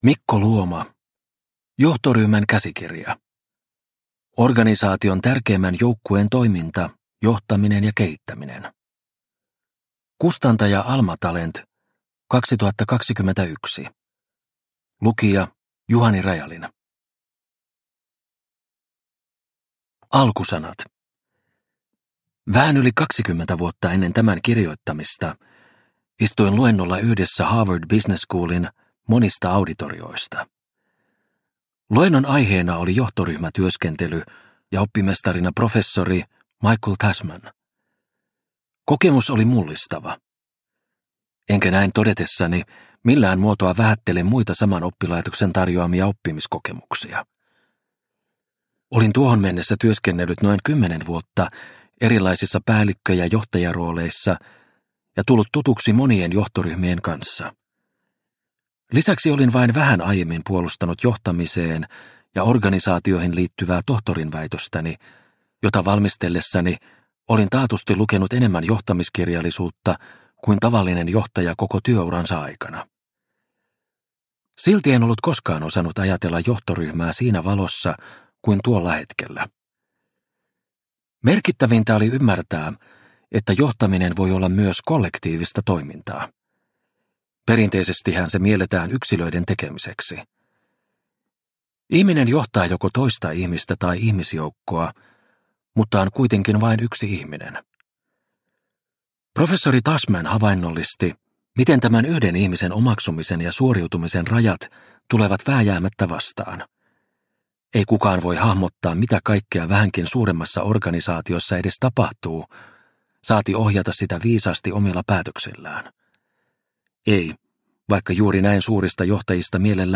Johtoryhmän käsikirja – Ljudbok – Laddas ner